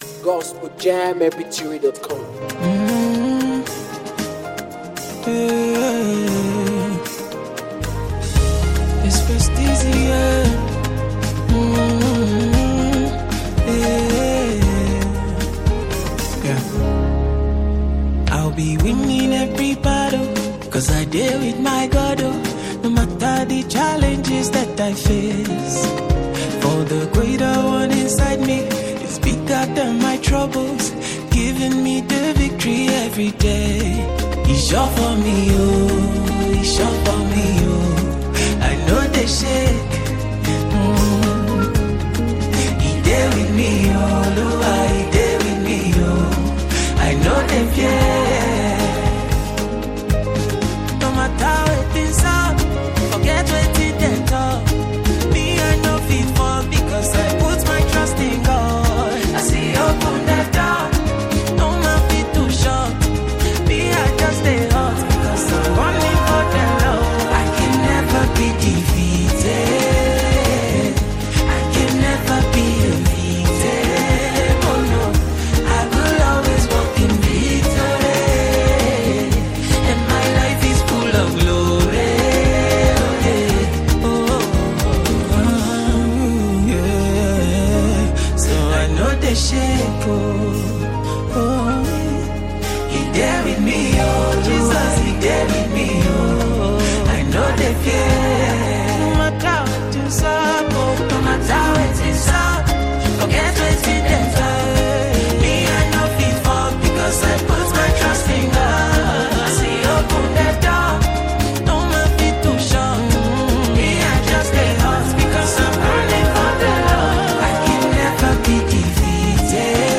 afro Gospel